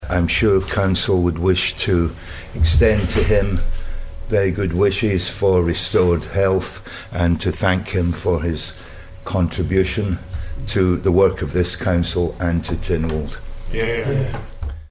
Tynwald President Steve Rodan paid thanks to him during a Legislative Council sitting yesterday: